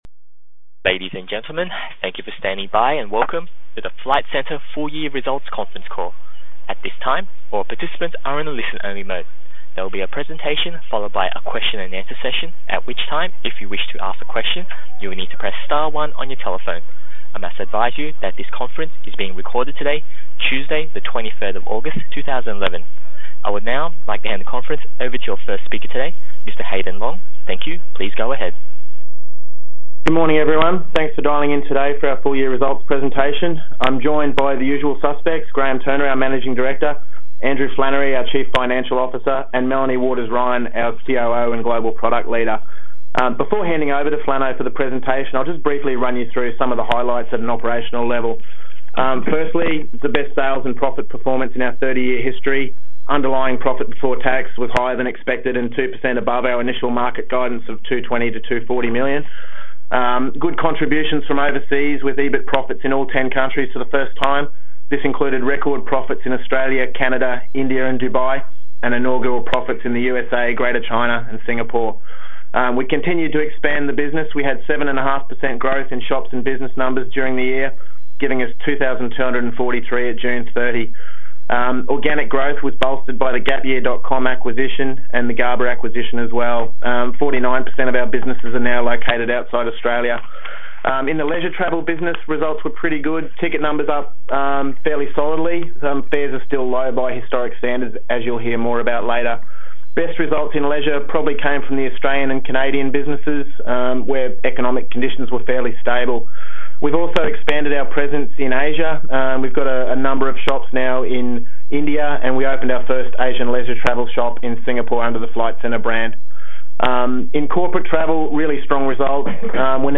Recording of FLTs 201011 full year result conference call